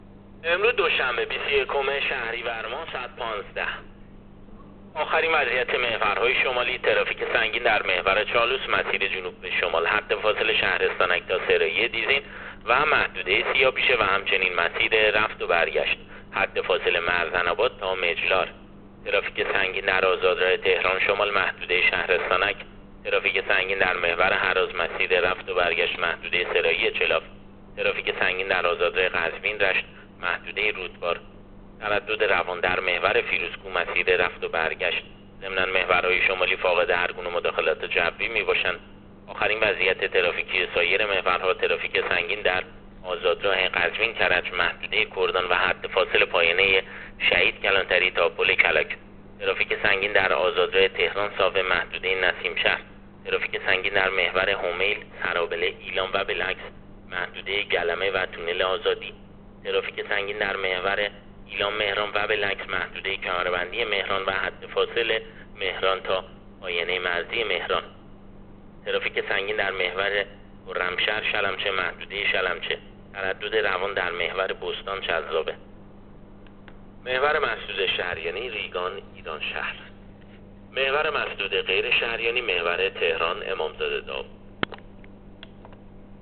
گزارش رادیو اینترنتی از آخرین وضعیت ترافیکی جاده‌ها تا ساعت ۱۵ بیست و یکم شهریور؛